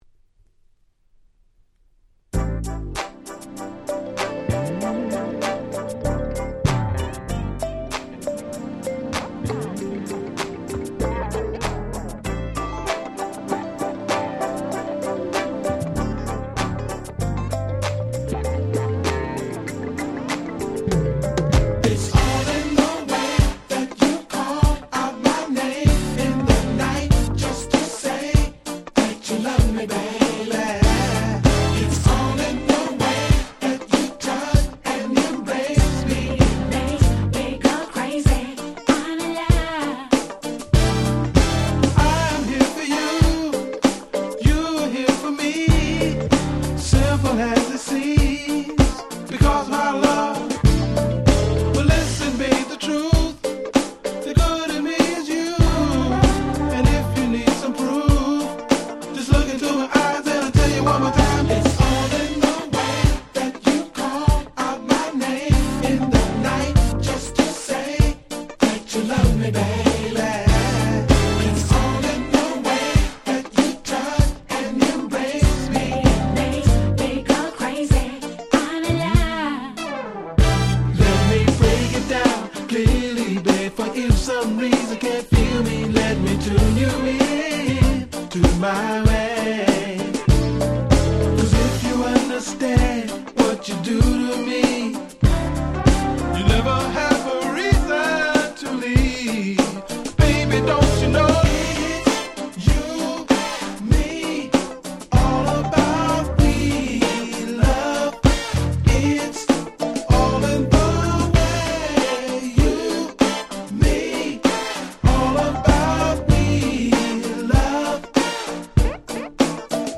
03' Nice Smooth R&B !!